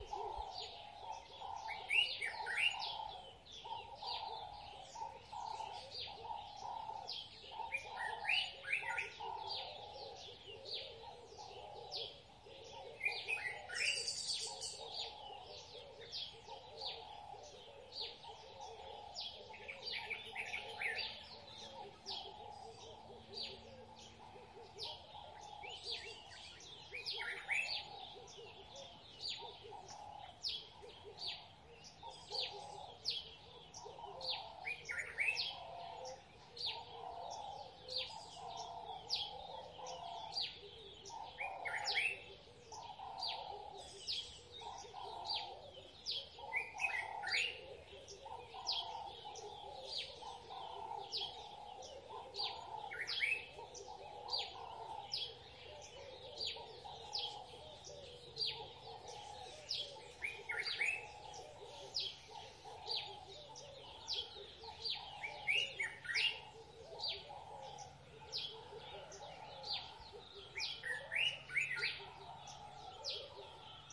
打开窗户的声音 " 早晨的鸟儿安博 - 声音 - 淘声网 - 免费音效素材资源|视频游戏配乐下载
凌晨4点在郊区后花园录制